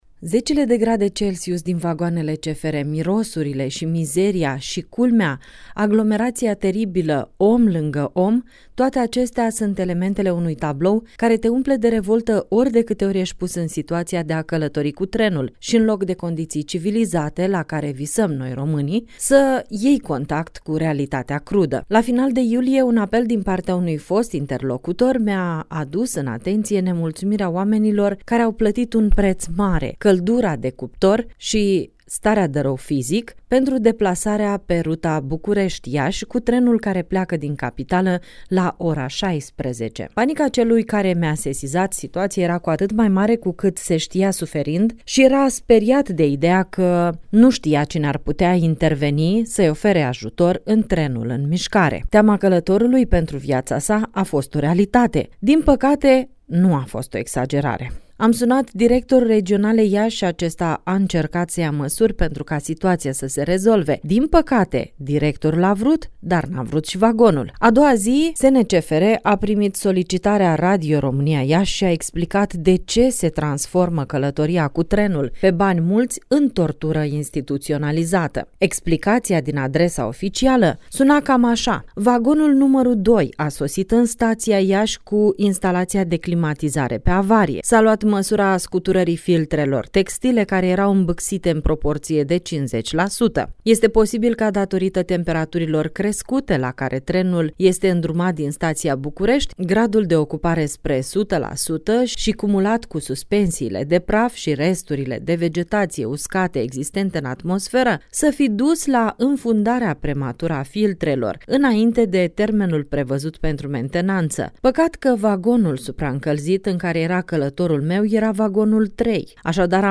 din interviul în exclusivitate